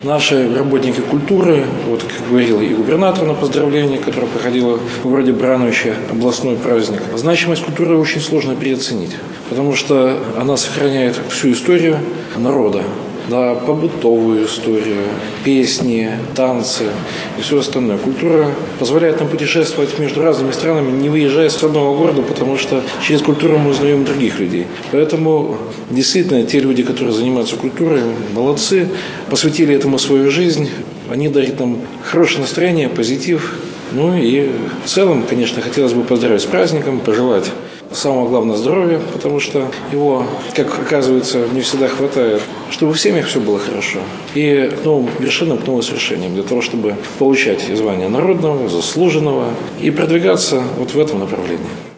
Творчество объединяет и вдохновляет, мотивирует человека к созиданию, отметил, поздравляя созвездие талантливых и креативных людей  с праздником председатель  Барановичского горисполкома Михаил Баценко.
Торжественный прием представителей культурного сообщества города состоялся   в большом зале горисполкома.